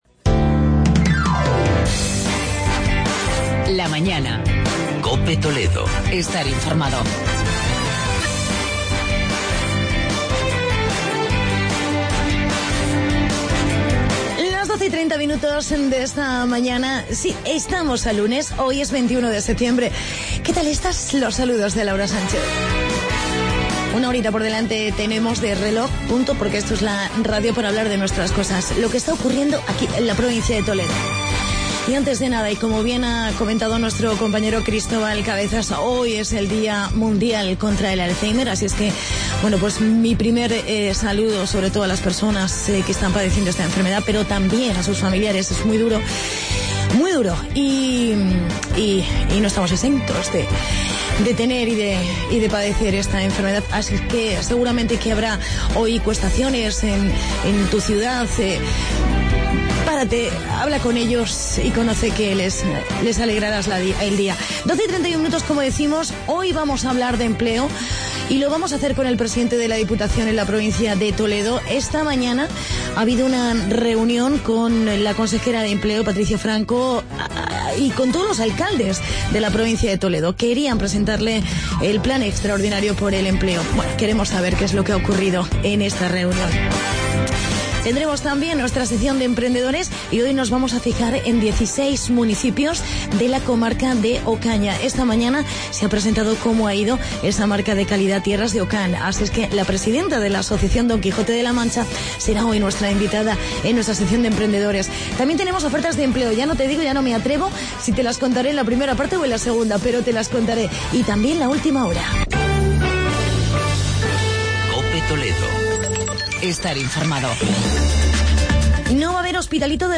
Entrevista con el Pte de la Diputación de Toledo, Álvaro Gutierrez y con la pta de la Asoc.